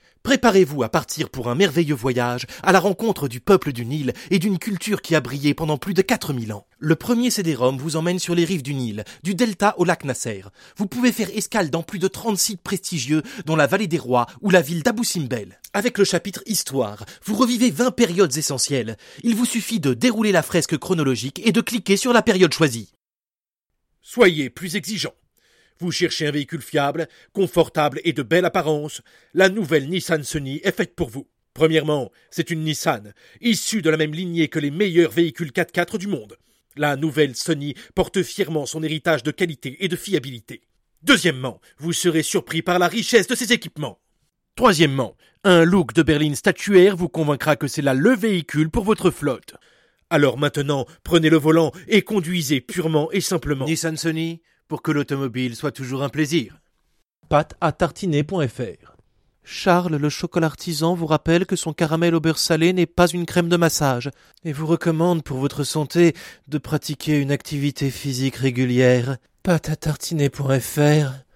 Voix Pub